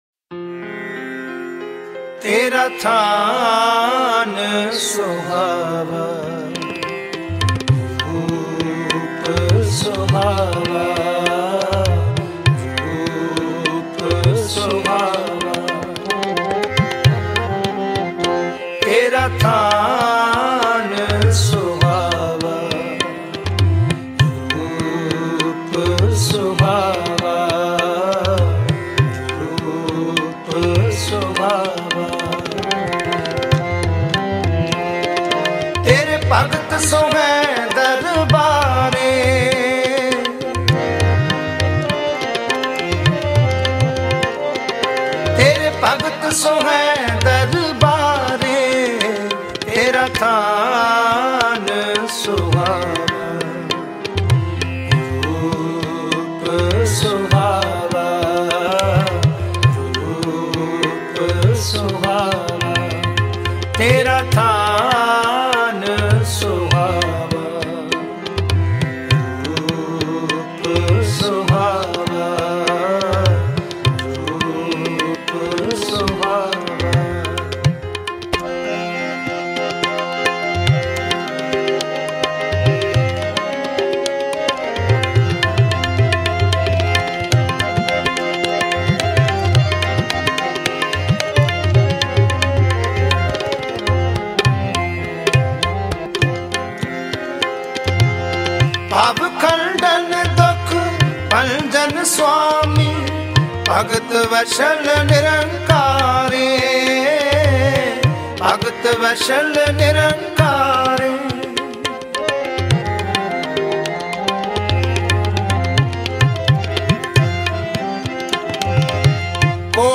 Sri Darbar Sahib Golden Temple Kirtan